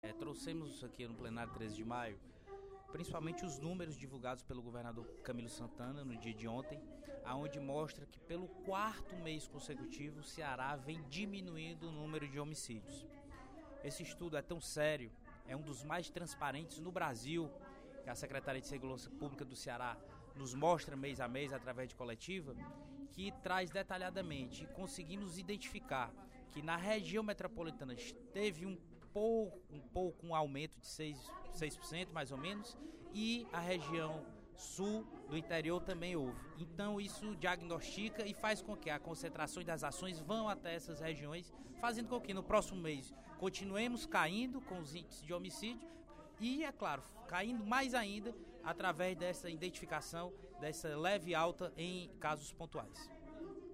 O deputado Júlio César Filho (PTN), vice-líder do Governo, no primeiro expediente da sessão plenária desta quarta-feira (03/06), destacou as ações pontuais na área da segurança implantadas pelo Governo do Ceará.